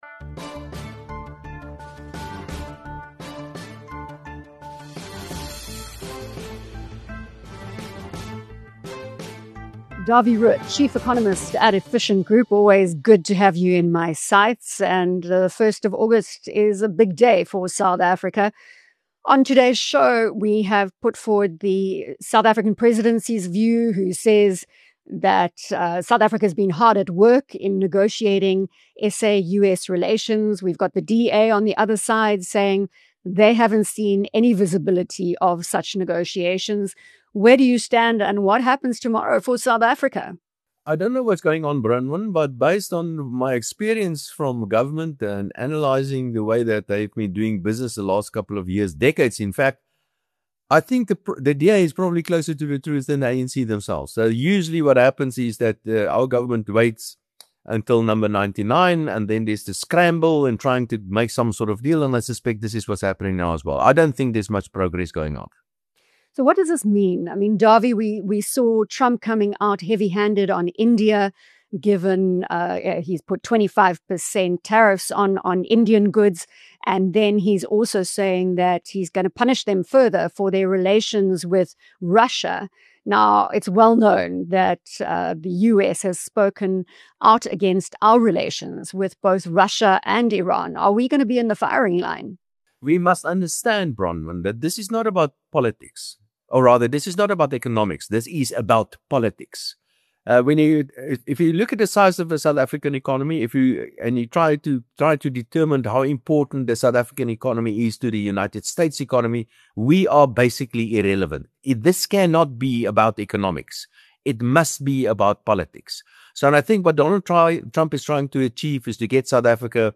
Economist Dawie Roodt warns that looming US tariffs on South African exports are politically driven and could significantly damage the country's fragile economy. In a BizNews interview, he criticises government inaction, highlights geopolitical risks, and calls for urgent reform. With economic growth stagnating, Roodt stresses the need for a new political direction and smarter international alignment.